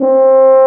BRS TUBA F0P.wav